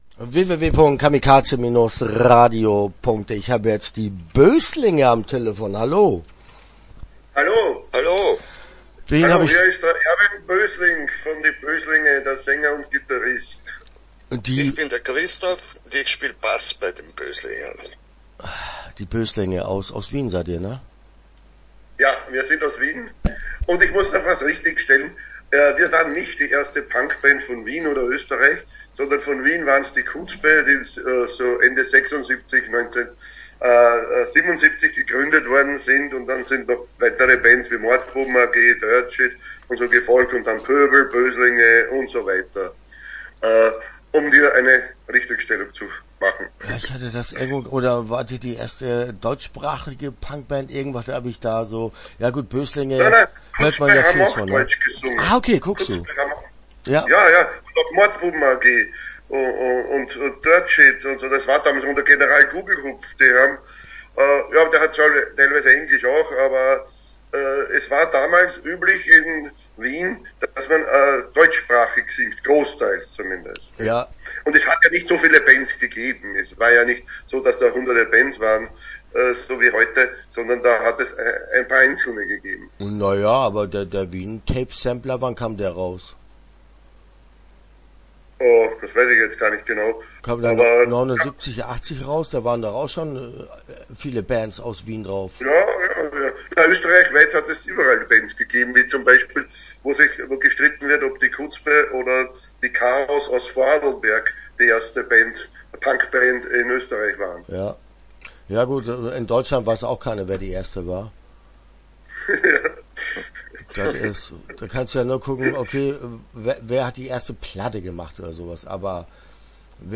Die Böslinge - Interview Teil 1 (12:59)